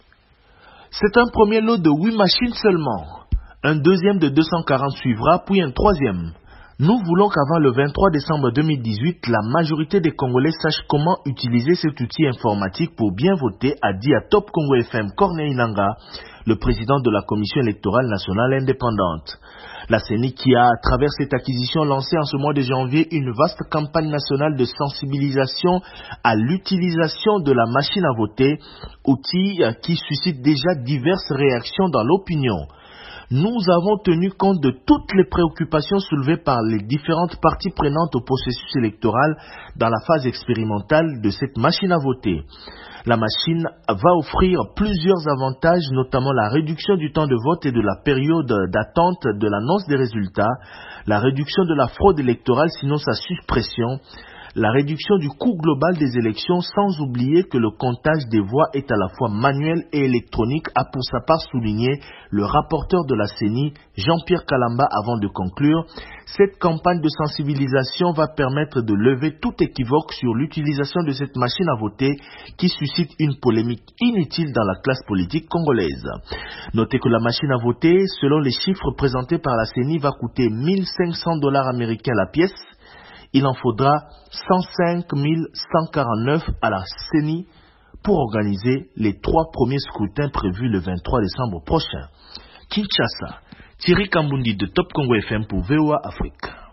Reportage de Top Congo FM à Kinshasa pour VOA Afrique